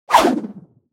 دانلود آهنگ تصادف 23 از افکت صوتی حمل و نقل
دانلود صدای تصادف 23 از ساعد نیوز با لینک مستقیم و کیفیت بالا
جلوه های صوتی